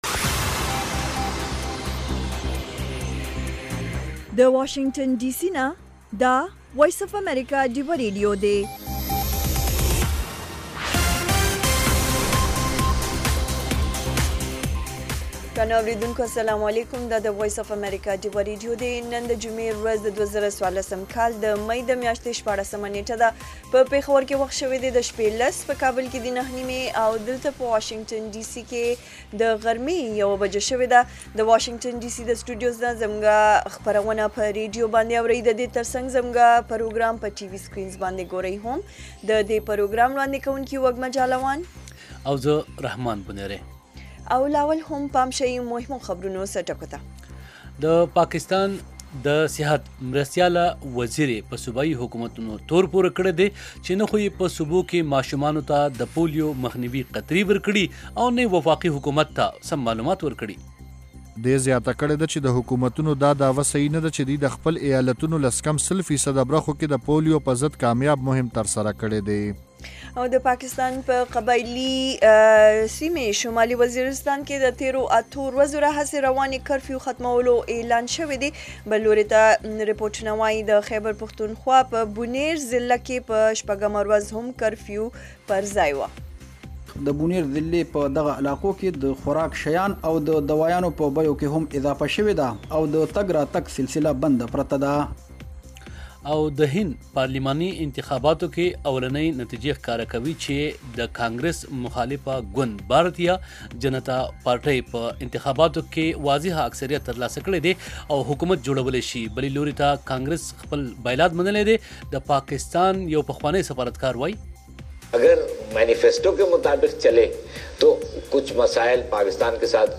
د وی او اې ډيوه راډيو ماښامنۍ خبرونه چالان کړئ اؤ د ورځې د مهمو تازه خبرونو سرليکونه واورئ.